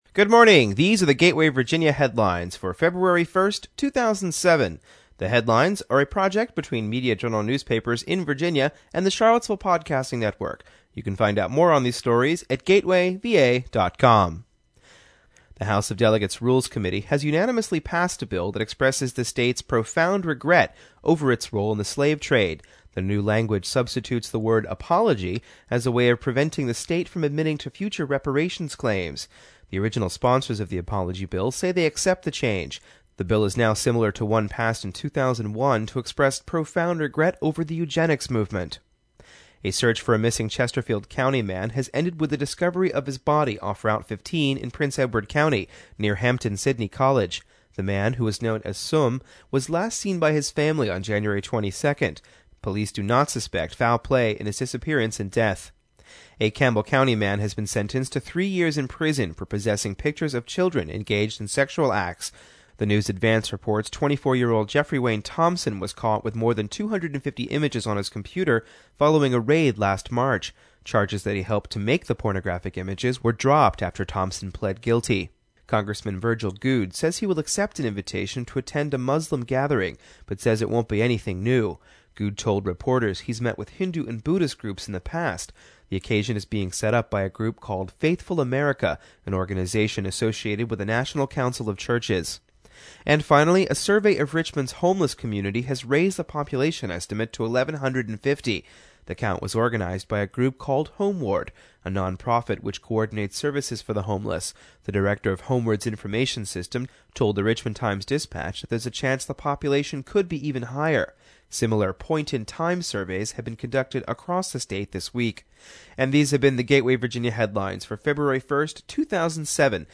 Every weekday we produce a two-minute summary of the daily headlines from around Central Virginia, with the goal of keeping you informed of whatGuv,!v,,us going on. ItGuv,!v,,us an old-fashioned radio newscast, here on the Charlottesville Podcasting Network.